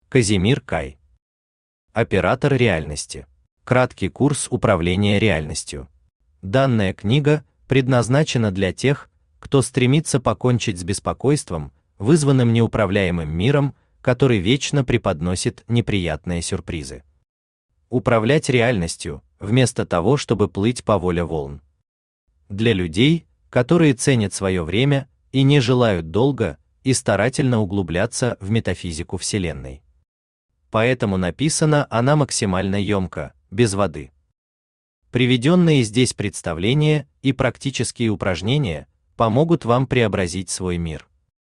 Аудиокнига Оператор реальности | Библиотека аудиокниг
Aудиокнига Оператор реальности Автор Казимир Кай Читает аудиокнигу Авточтец ЛитРес.